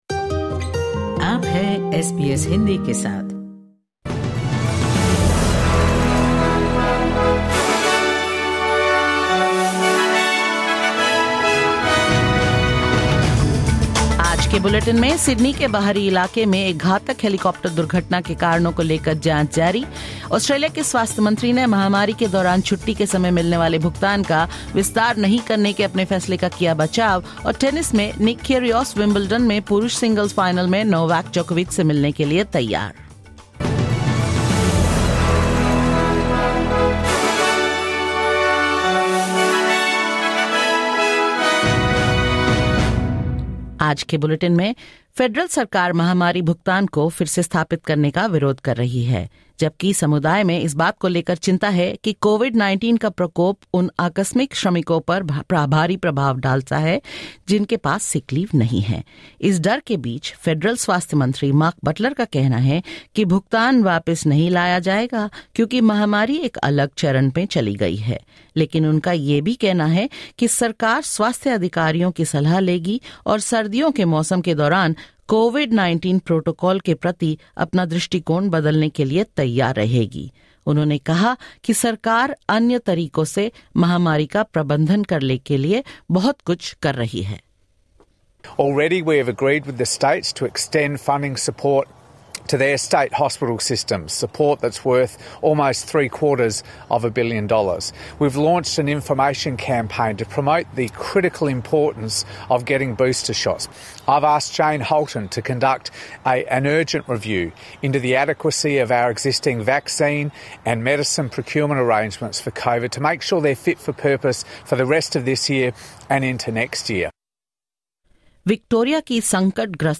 In this latest SBS Hindi bulletin: Federal Health Minister Mark Butler defends his decision to not reinstate pandemic leave payments: Australia's transport safety watchdog launches inquiry into a helicopter crash in Sydney's outskirts; In sports, Nick Kyrgios prepares to meet Novak Djokovic in tonight's Wimbledon men's final and more.